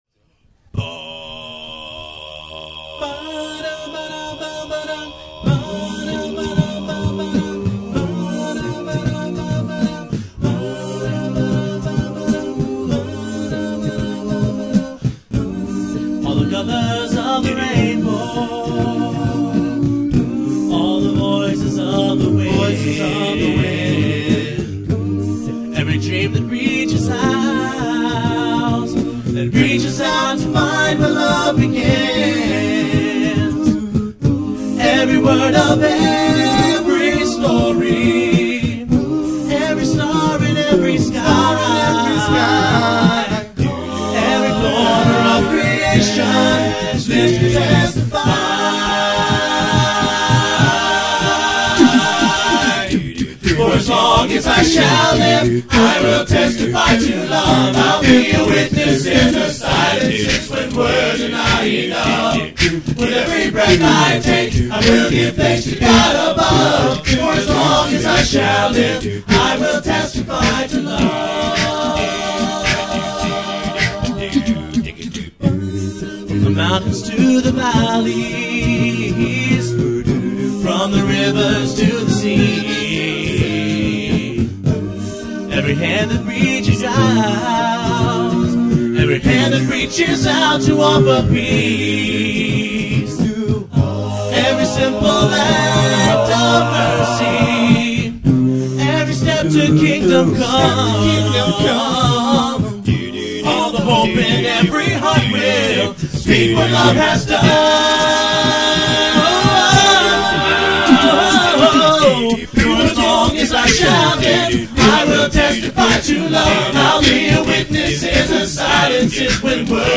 And no Instruments